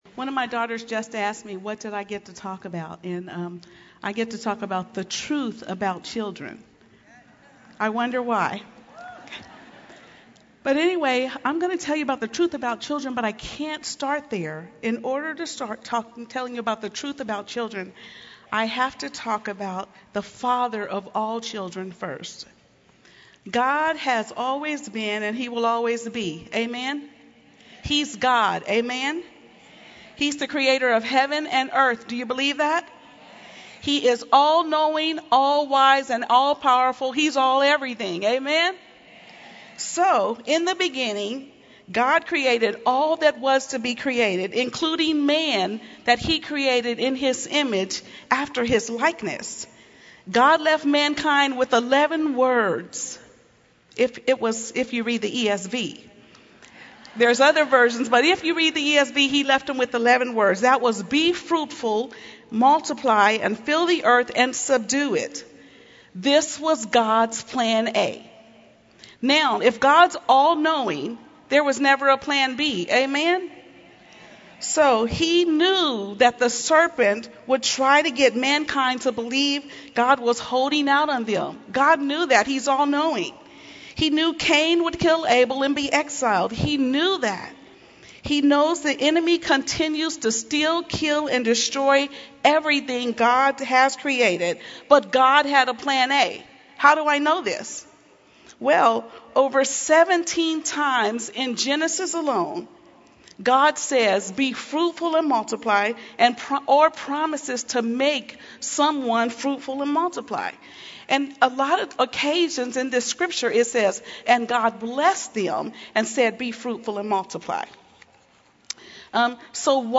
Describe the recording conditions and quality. Message 8: The Truth about Children | True Woman '18 | Events | Revive Our Hearts